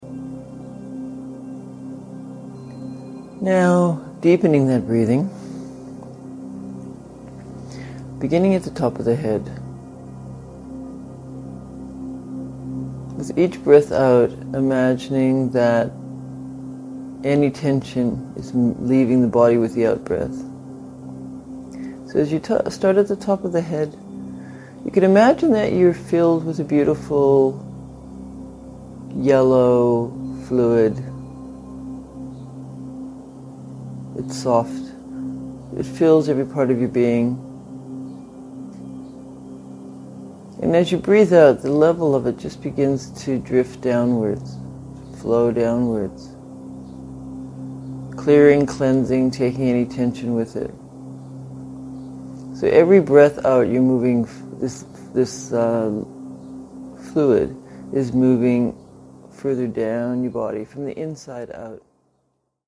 Morning Embodiment Meditation